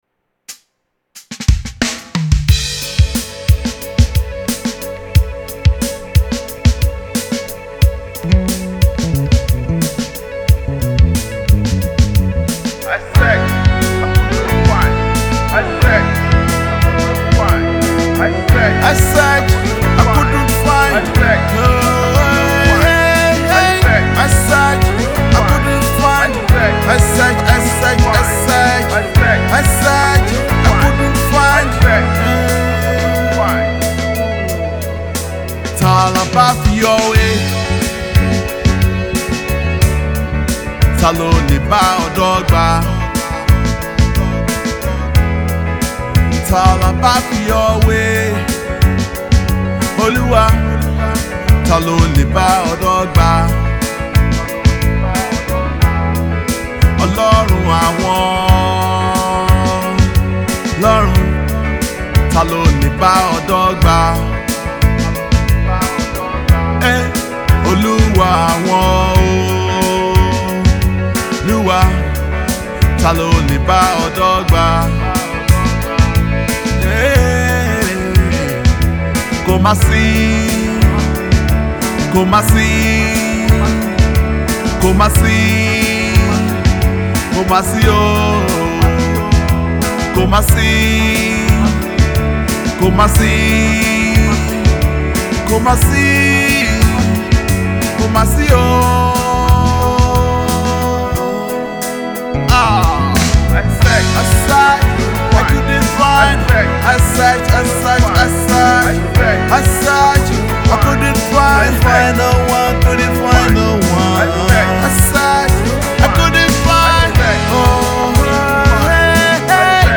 heartfelt song of thanksgiving and praise